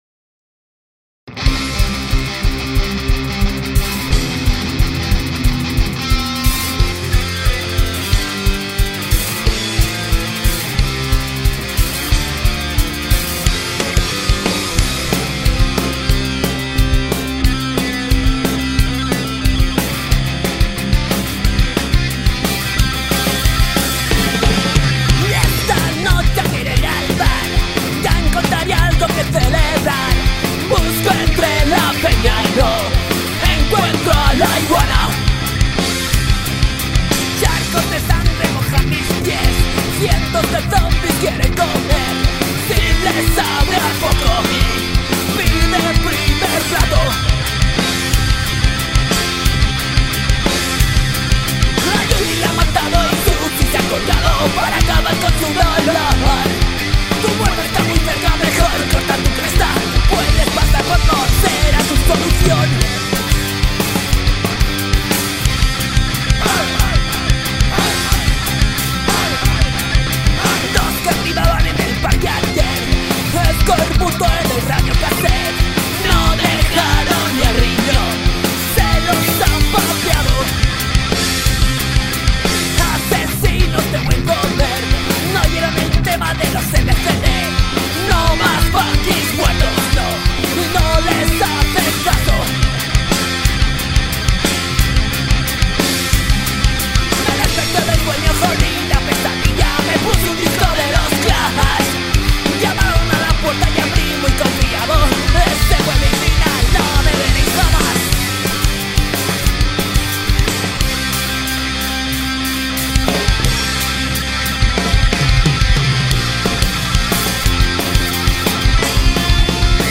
Estilo: Punk Rock